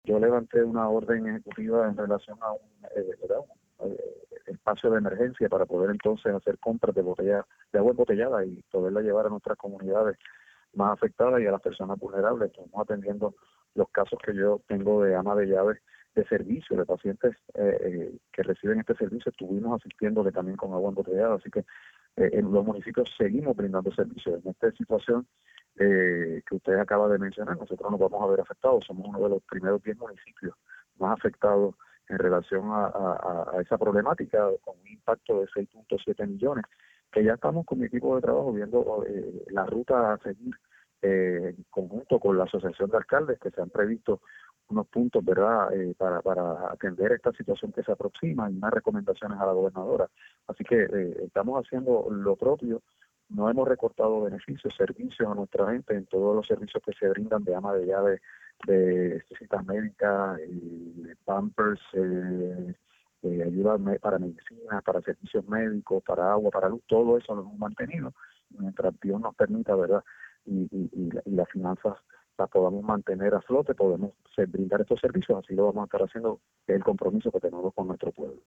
En momentos en los que se discute el impacto de la eliminación de ingresos como la congelación del impuesto al inventario, el alcalde destacó que es en estos momentos en los que los alcaldes y alcaldesas asisten a sus ciudadanos con, por ejemplo, el suplido de agua embotellada, inversión que aún no han culminado de contabilizar.